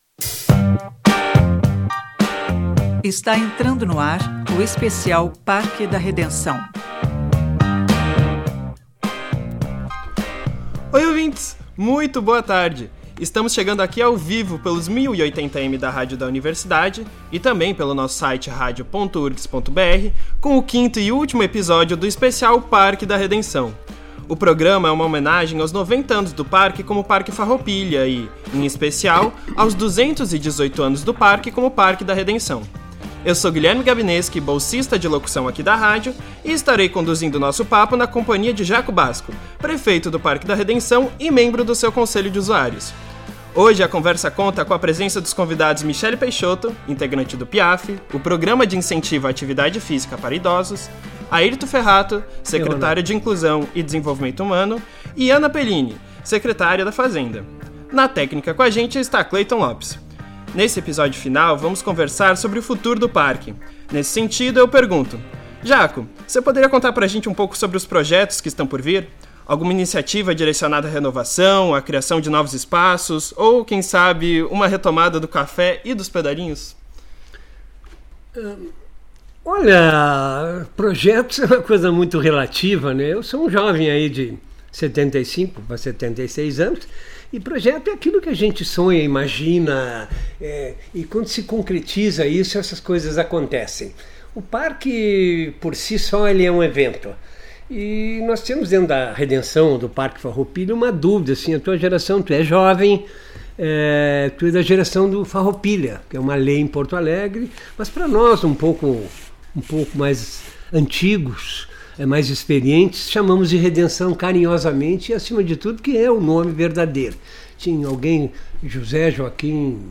ESPECIAL REDENÇÃO AO-VIVO 19-12-25.mp3